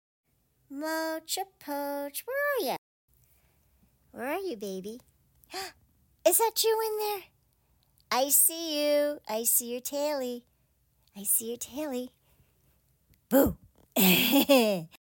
makes up silly songs